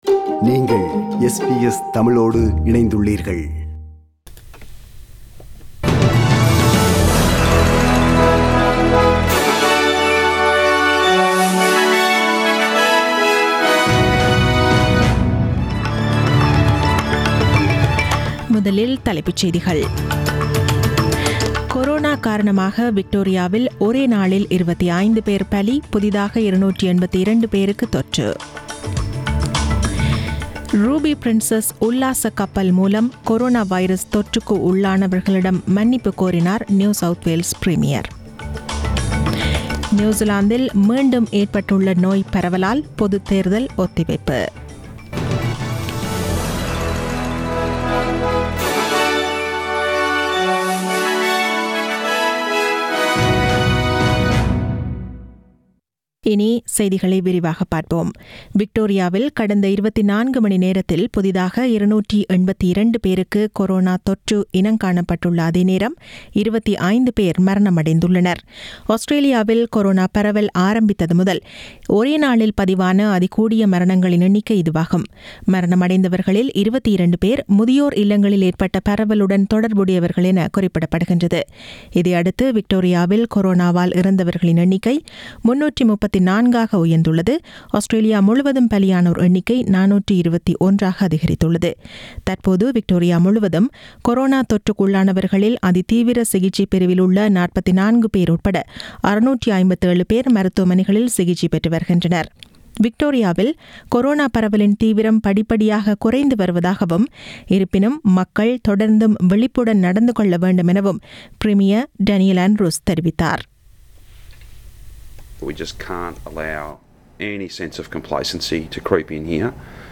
The news bulletin was aired on 17 August 2020 (Monday) at 8pm.